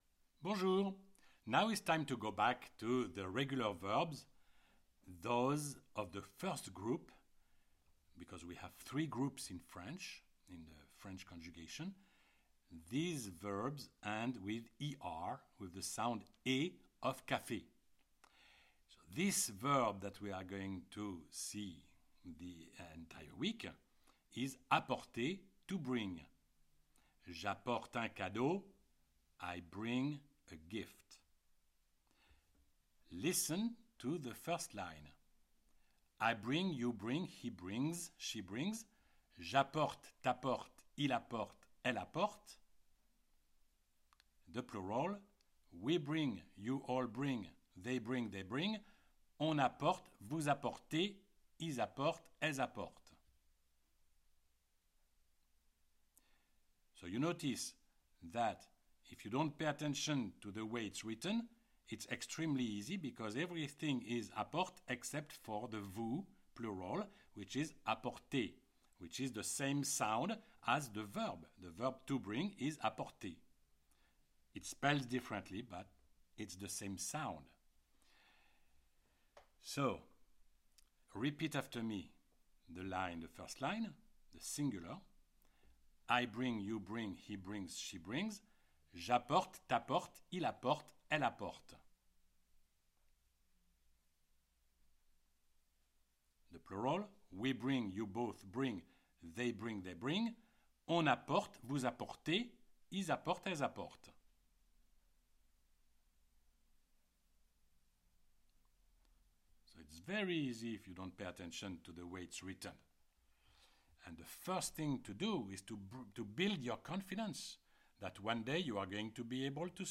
THAT IS ALSO WHY I SIMPLY ASK YOU TO IMITATE ME OVER AND OVER USING A LANGUAGE THAT IS CONTRACTED SO THAT YOU WILL FEEL EMPOWERED WHEN THE TIME COMES FOR YOU TO COMMUNICATE WITH THE FRENCH.